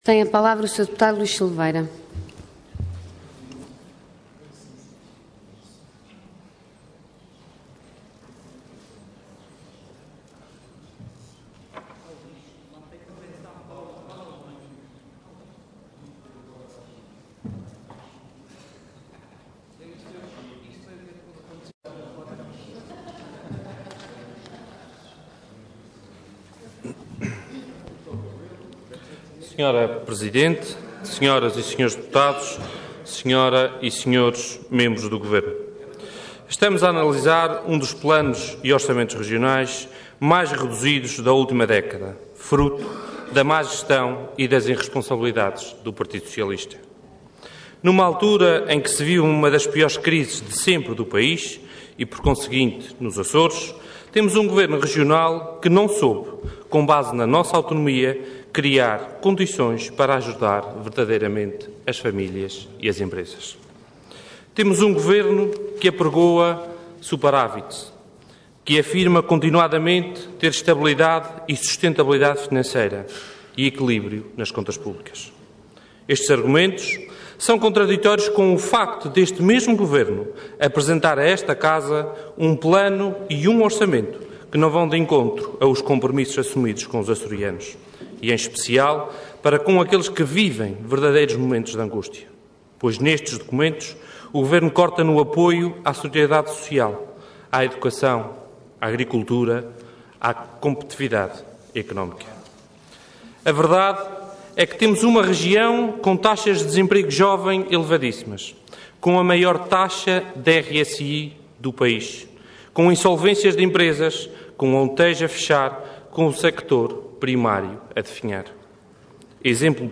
Intervenção Intervenção de Tribuna Orador Luís Silveira Cargo Deputado Entidade PS